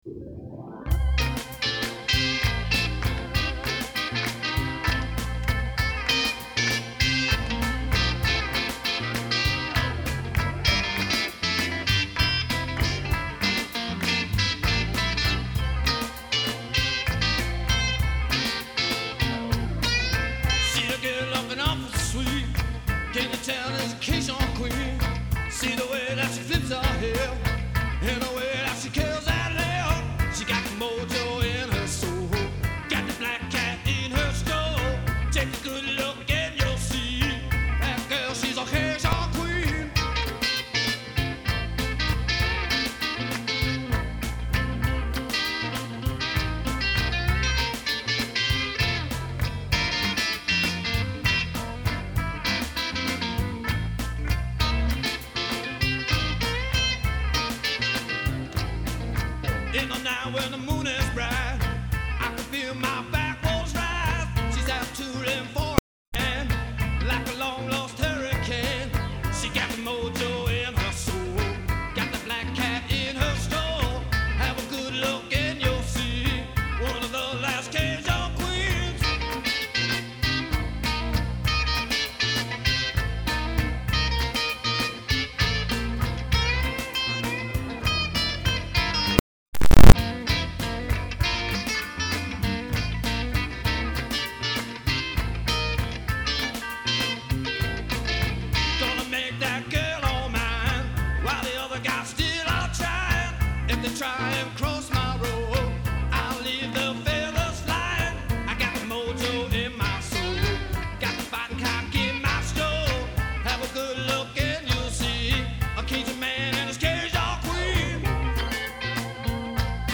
vocals
harmonica
keyboards
all guitars, bass